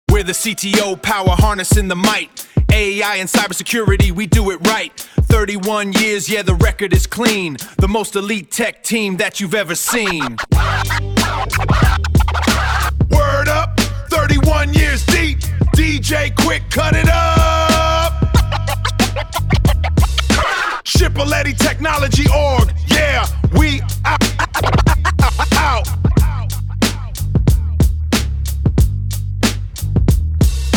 cto-rap.mp3